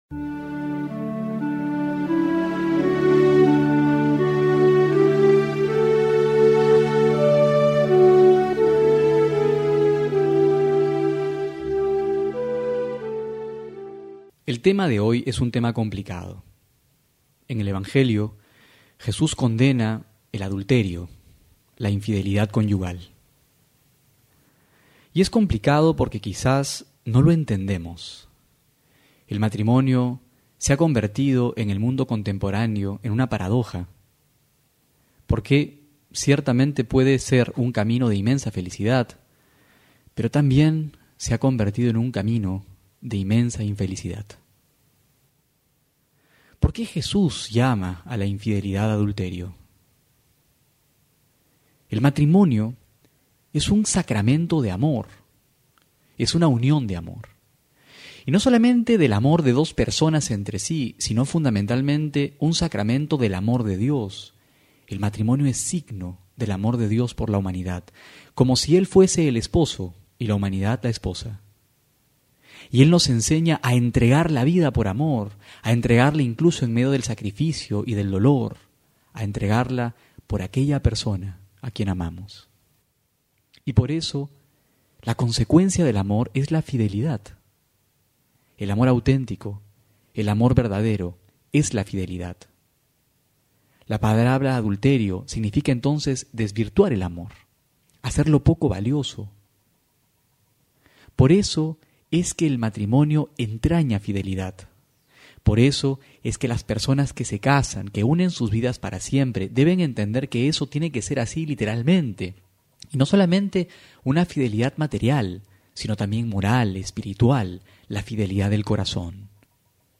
Homilía para hoy